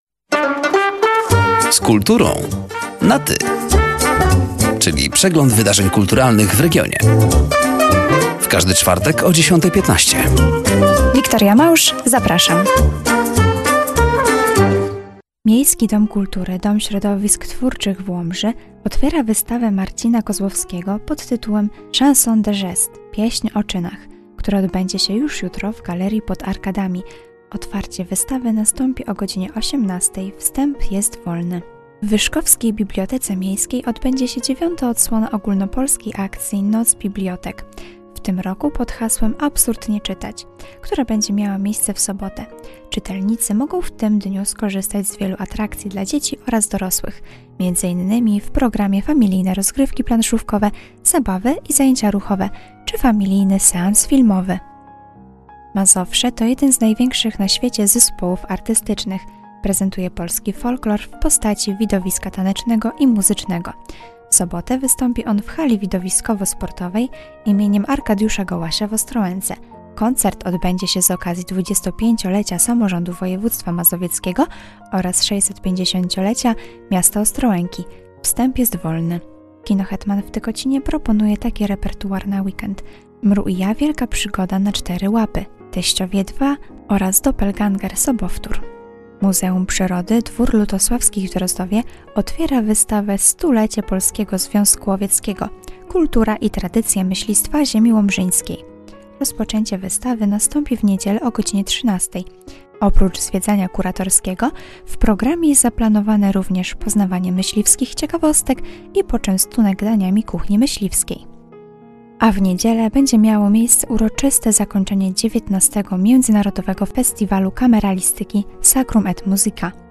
Zapraszamy do zapoznania się ze zbliżającymi wydarzeniami oraz do wysłuchania rozmowy.